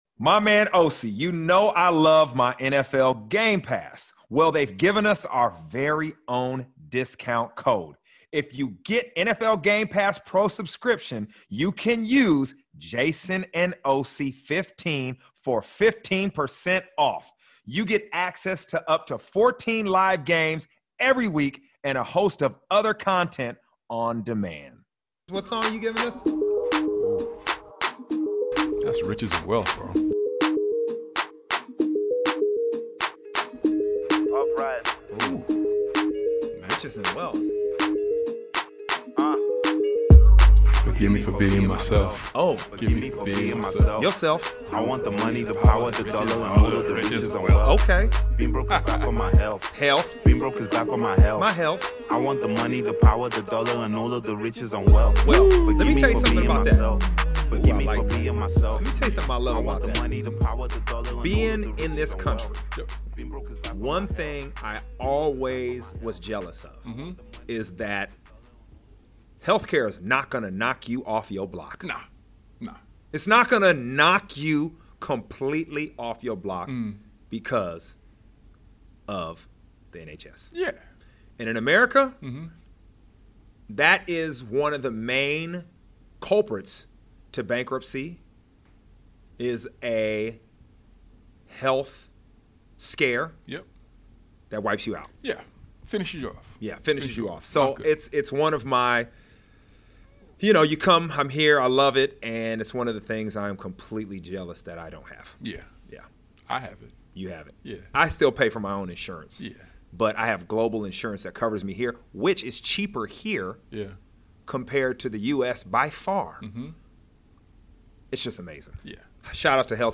In the Wildcard Round of the NFL Playoffs, Jason Bell and Osi Umenyiora break down all the action, brought to you by 888 sport & La-Z-Boy—official partners of NFL UK and Ireland. Jason and Osi discuss Justin Herbert’s struggles in the LA Chargers’ loss to the Houston Texans, while Derrick Henry and Lamar Jackson led their teams to a dominant home victory over the Pittsburgh Steelers.